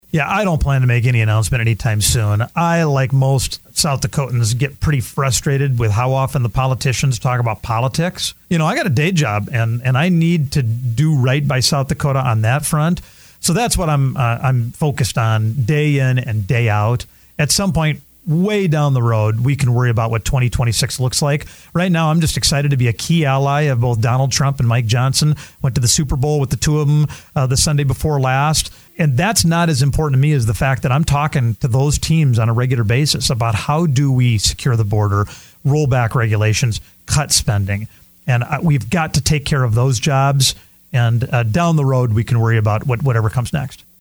During a stop at a Pierre radio station Wednesday afternoon (Feb. 19, 2025), Dusty Johnson said he will run for governor.